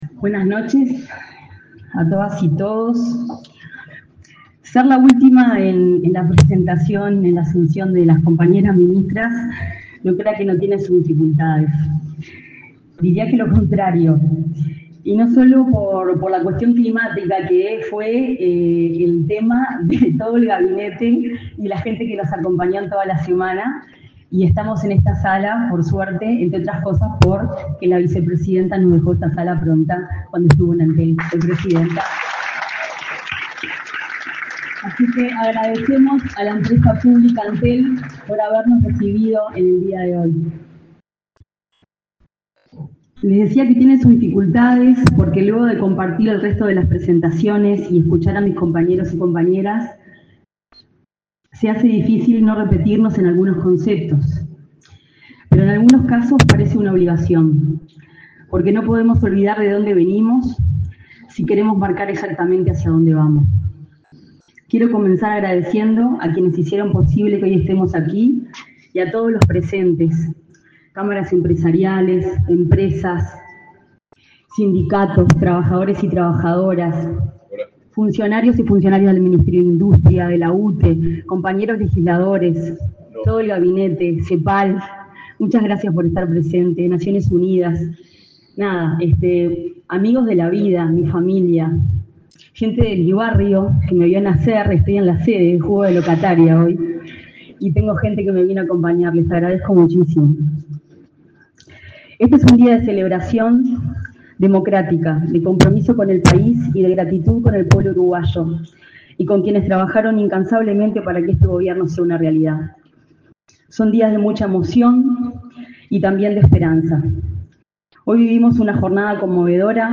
Palabras de la ministra de Industria, Energía y Minería, Fernanda Cardona
Palabras de la ministra de Industria, Energía y Minería, Fernanda Cardona 07/03/2025 Compartir Facebook X Copiar enlace WhatsApp LinkedIn El presidente de la República, profesor Yamandú Orsi, participó, este 7 de marzo, junto con la vicepresidenta, Carolina Cosse, en la asunción de autoridades del Ministerio de Industria, Energía y Minería, Fernanda Cardona como ministra y Eugenia Villar como subsecretaria.